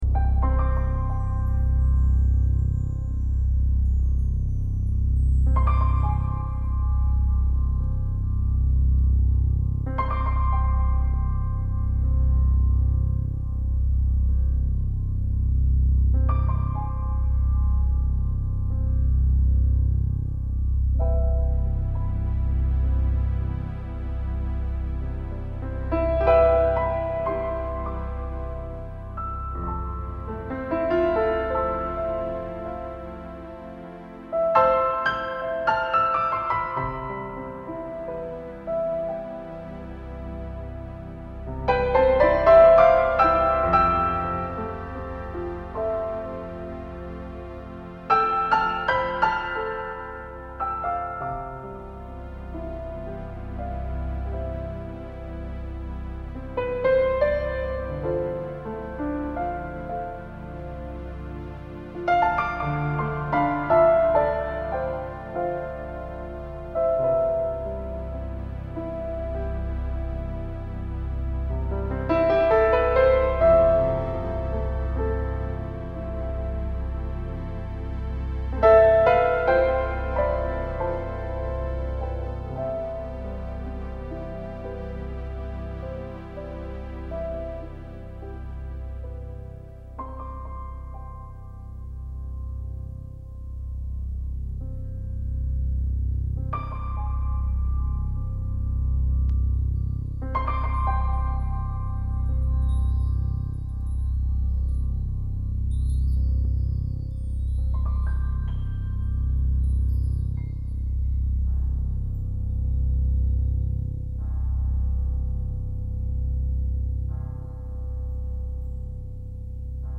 Composition, arrangement and performance.
Horror Theme Music